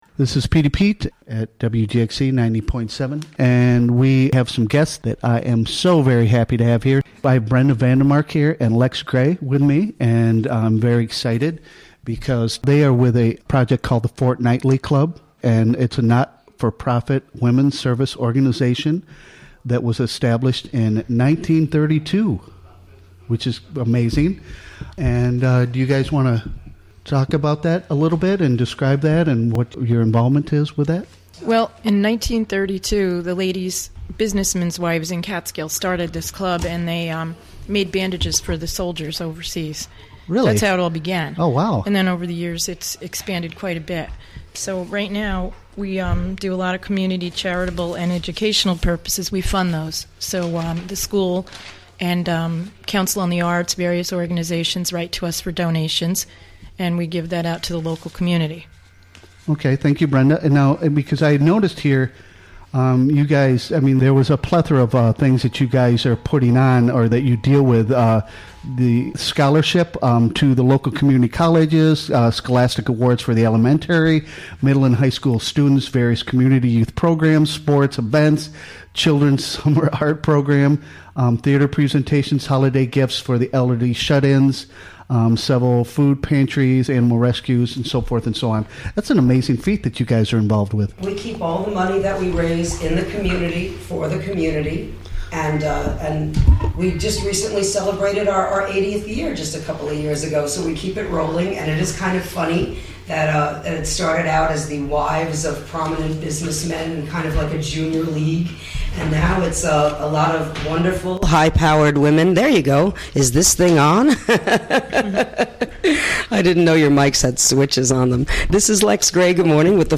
Recorded live during the WGXC Morning Show of Tuesday, May 9, 2017.